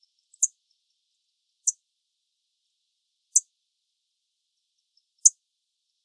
La presencia de estos sonidos puede indicar en algunas especies animales dominancia, apareamiento o cortejo; llamados de alerta al peligro; reconocimiento entre madres y crías etc. Este repositorio digital contiene grabaciones de fauna silvestre residente en la península de Baja California, resultado del proyecto de investigación en el área natural protegida Sierra de la Laguna.
Leiothlypis_celata.mp3